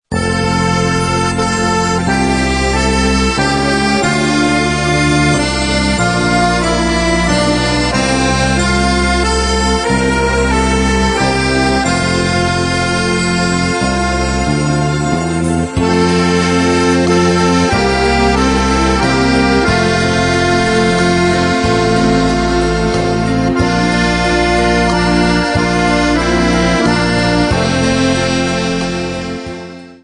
Besetzung: Akkordeon mit CD